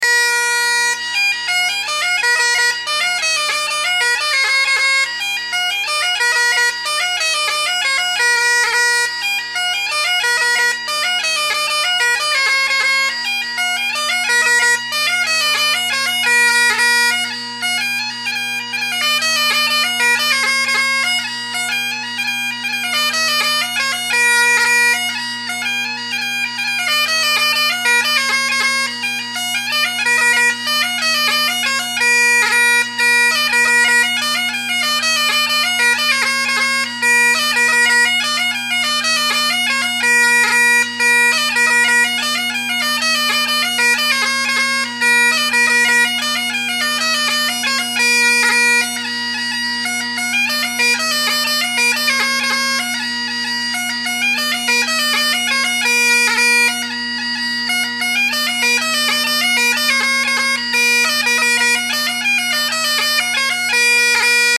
I had to tape the high G, E, and C each just a tiny tad with the final reed which is the one I made the most recordings on.
The top hand F, high G, and high A all sound very good. D is spot on, as is B. The other notes are a given.